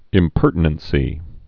(ĭm-pûrtn-ən-sē)